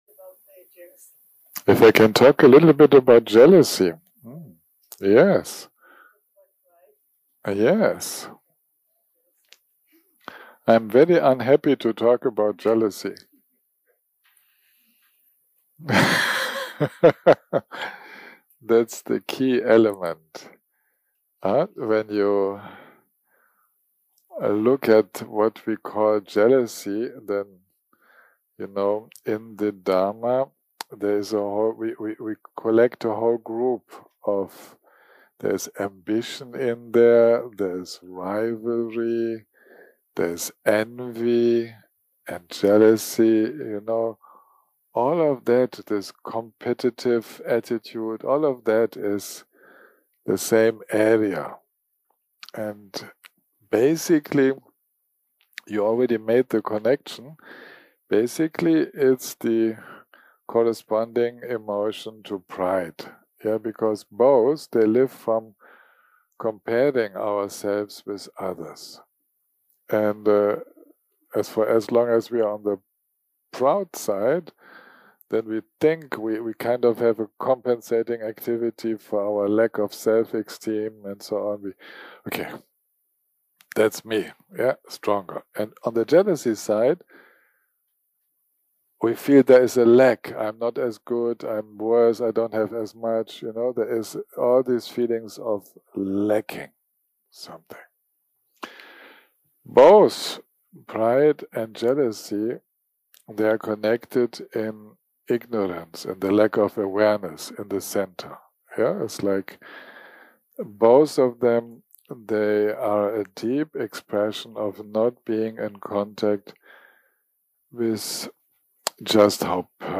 Dharma type: Questions and Answers שפת ההקלטה